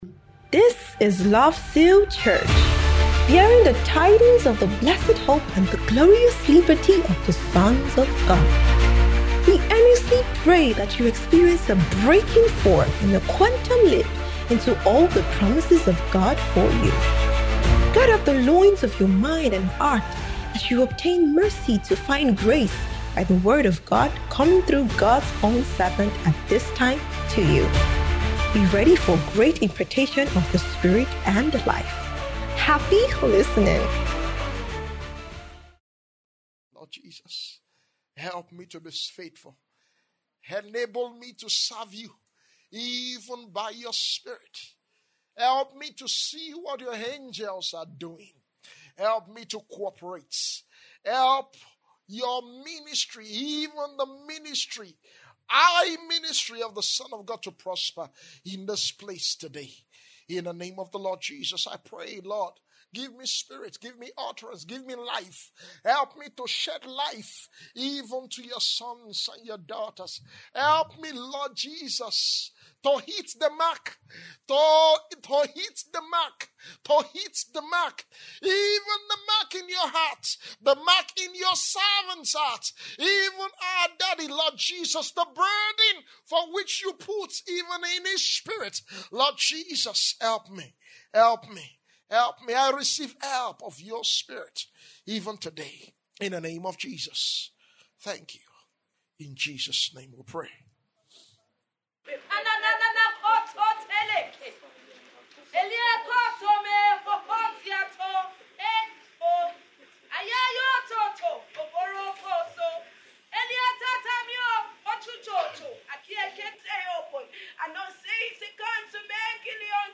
Transfiguration Bootcamp 2025 (Grand Finale)